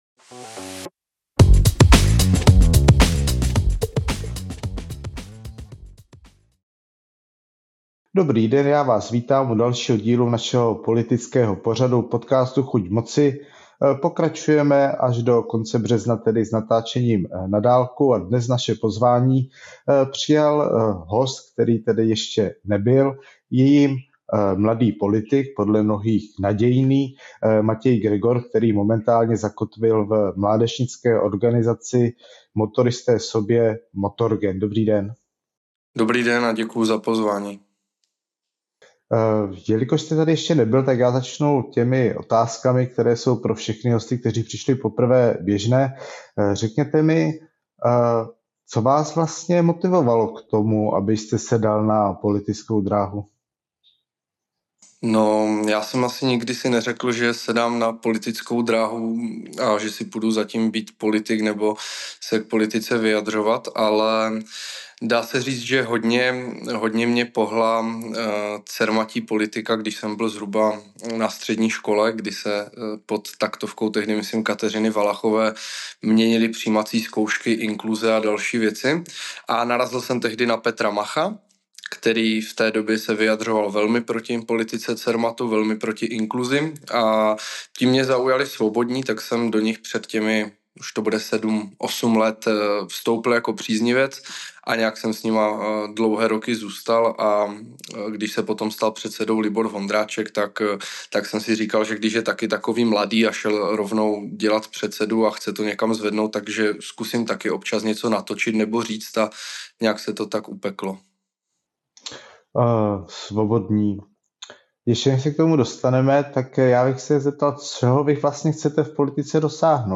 Natočeno ve studiu Datarun!